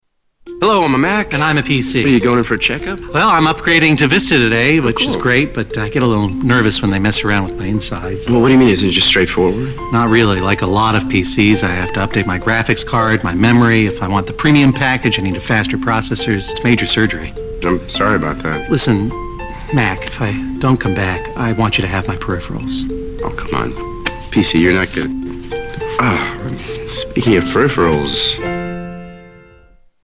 Tags: Media Apple Mac Guy Vs. PC Guy Commercial Justin Long John Hodgeman